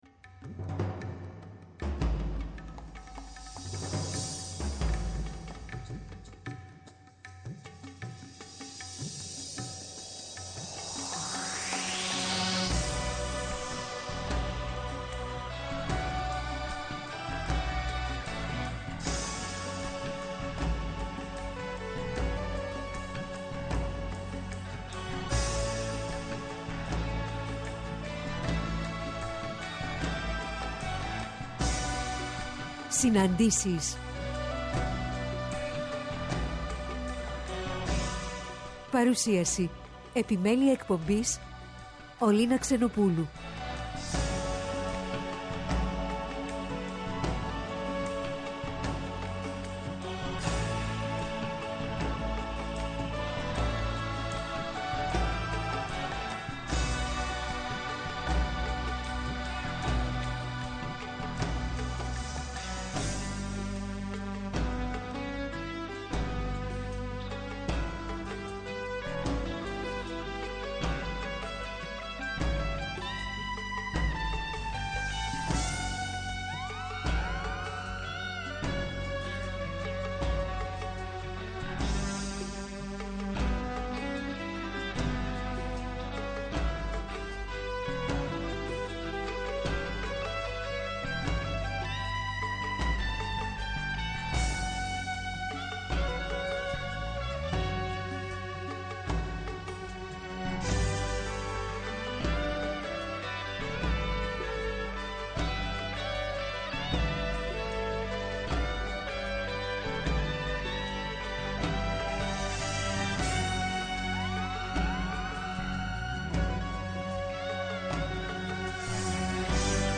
Κυριακή 09-10-22 καλεσμένοι στις « Συναντήσεις» του Πρώτου Προγράμματος, ώρα 16:00 -17:00: